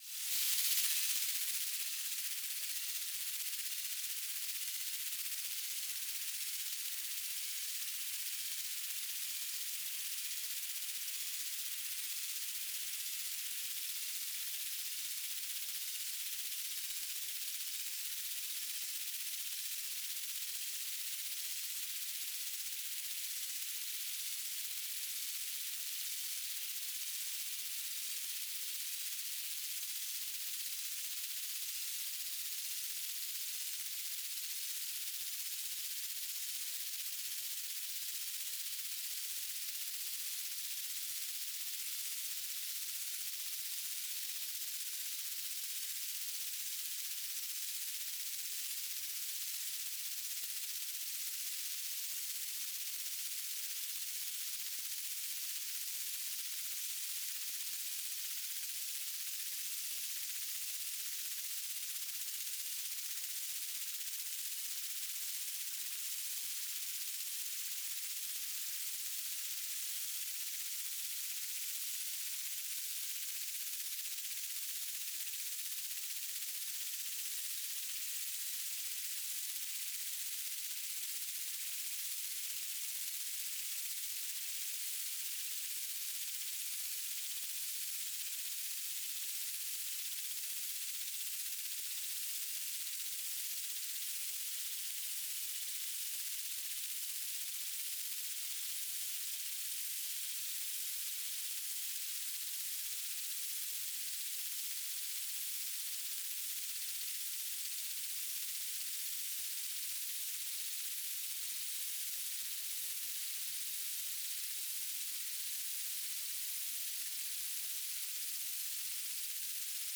"transmitter_mode": "BPSK",